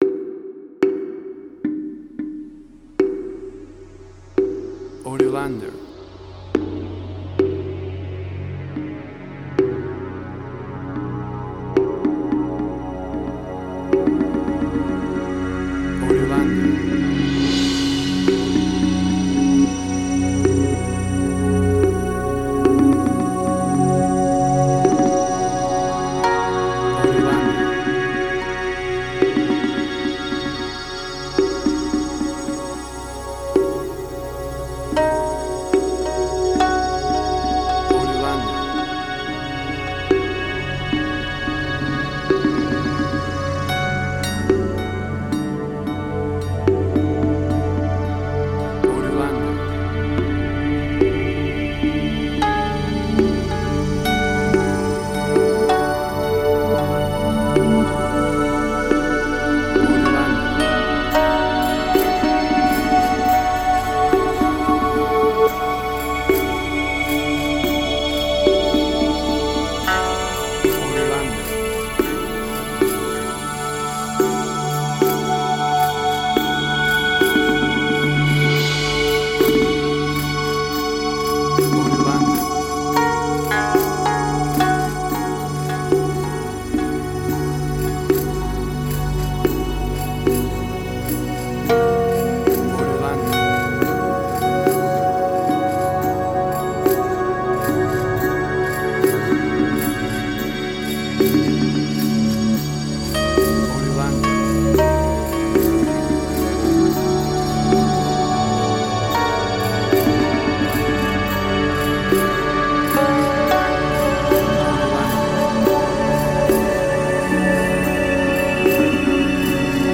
New Age.
emotional music
Tempo (BPM): 55